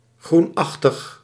Ääntäminen
Ääntäminen France: IPA: /vɛʁ.datʁ/ Haettu sana löytyi näillä lähdekielillä: ranska Käännös Ääninäyte 1. groenachtig Suku: f .